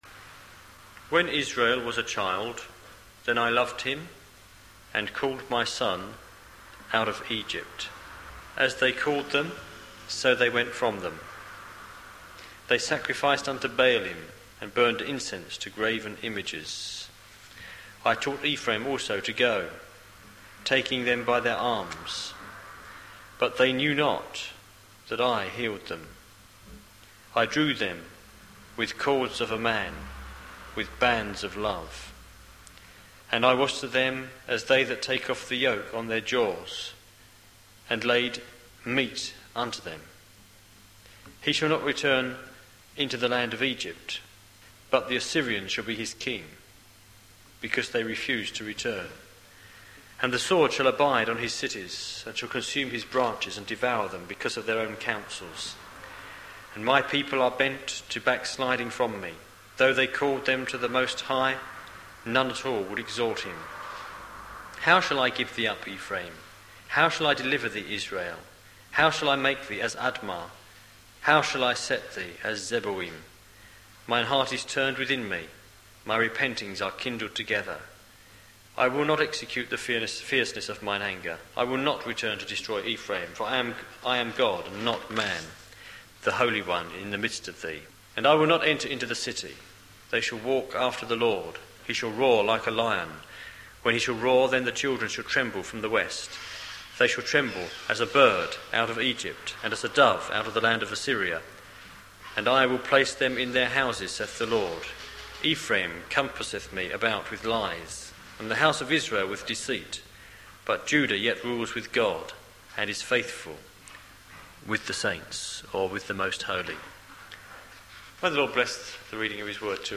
The Church at Gun Hill - Online Bible Study